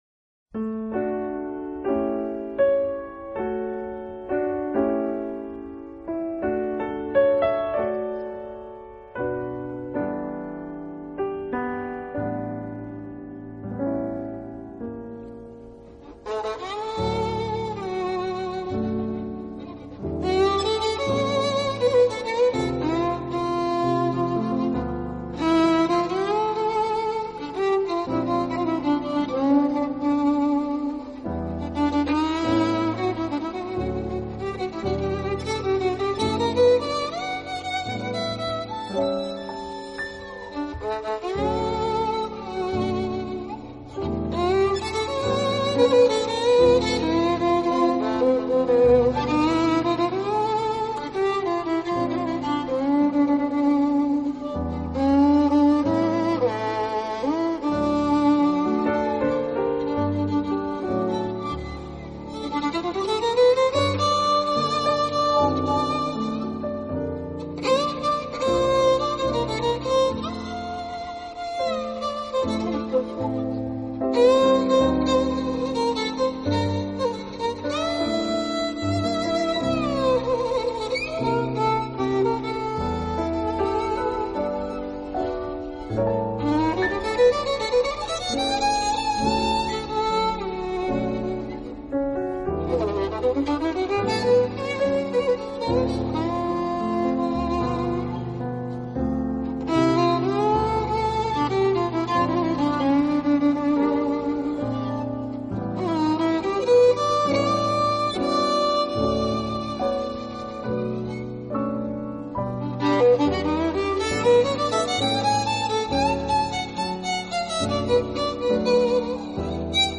【钢琴VS小提琴】
专辑种类：Jazz
人之手，小提琴音色温暖醇厚，松香味儿十足。